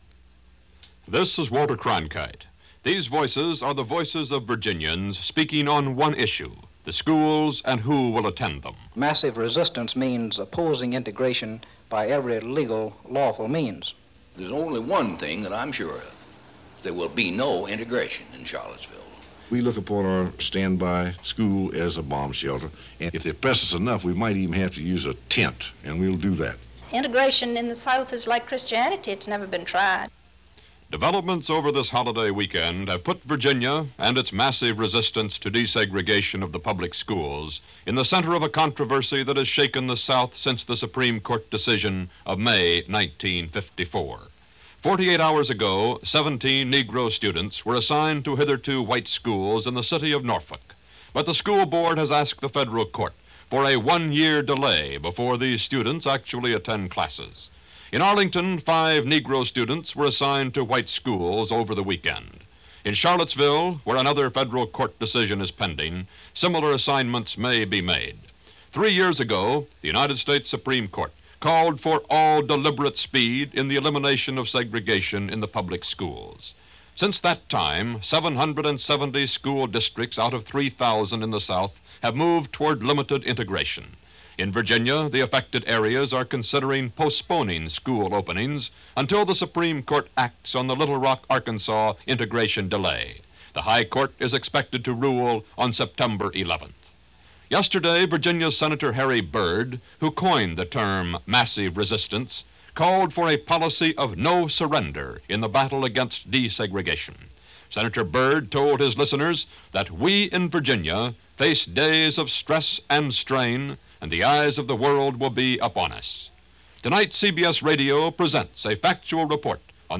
- narrated by Walter Cronkite -
cbs-radio-virginia-integration-1958.mp3